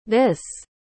Xát răng /T͟H/ | comic Anh Việt